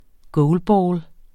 Udtale [ ˈgɔwlˌbɒːl ]